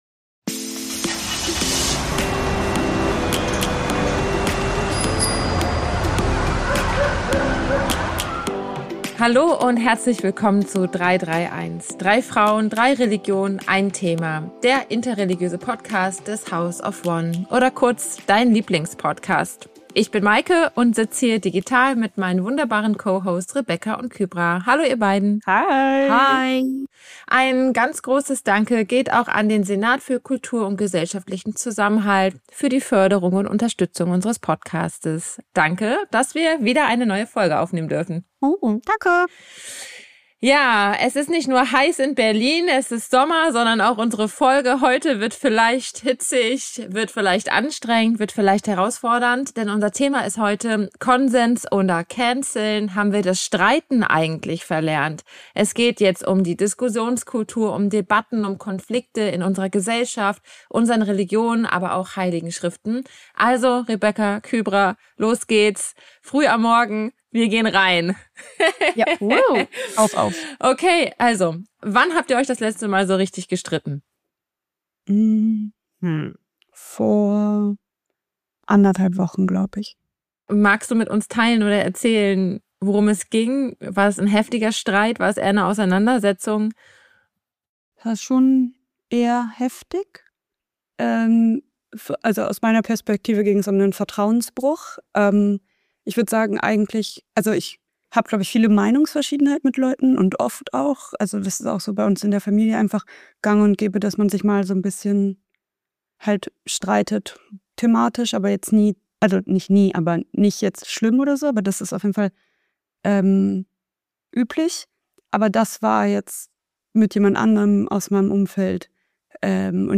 Wir sprechen über unseren Glauben, unsere Erfahrungen, unsere Wurzeln. Wir tauschen uns aus, lachen miteinander, streiten miteinander und bleiben trotzdem immer im Gespräch. Religion aus dem Leben, nicht aus dem Lehrbuch.